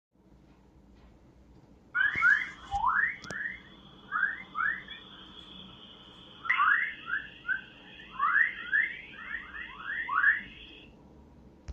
«Чирикающие» хоровые волны, угрожающие космическим аппаратам, обнаружили вдали от Земли
И вот впервые ученые засекли их «чириканье» вдали от нашей планеты.
chorus.mp3